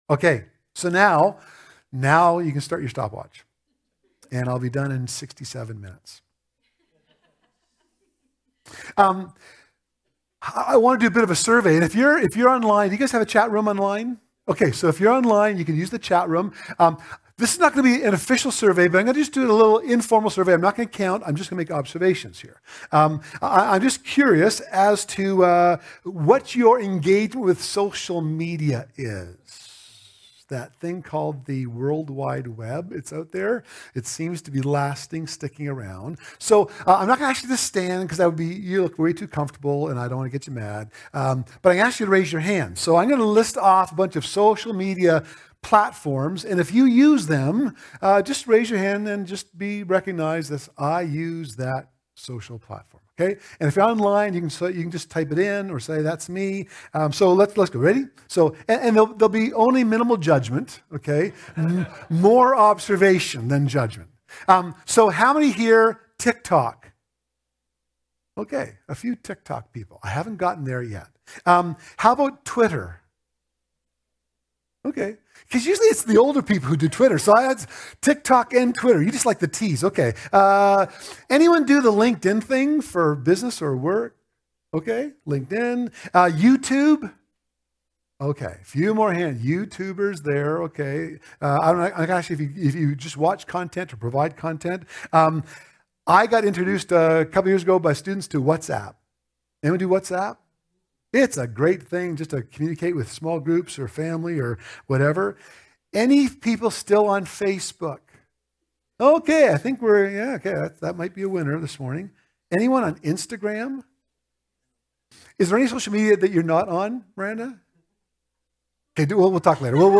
Sermons | Devon Community Church